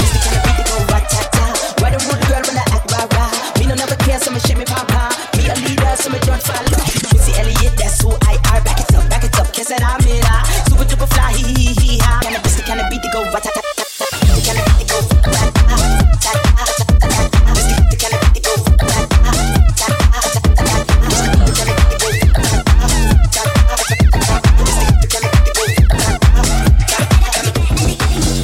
Live mix
house-deep-techno
Genere: deep,house,techno,latin,hit,remix mashup